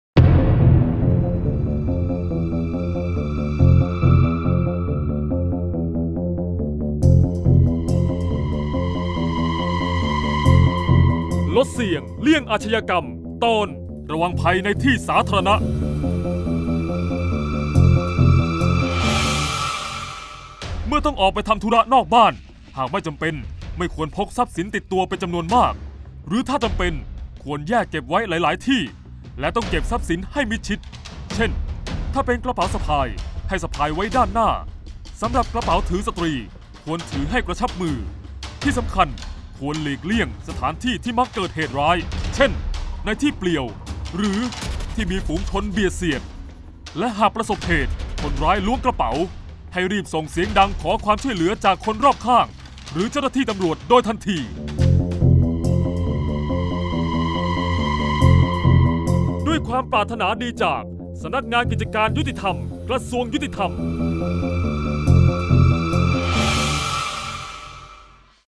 เสียงบรรยาย ลดเสี่ยงเลี่ยงอาชญากรรม 04-ระวังภัยที่สาธารณะ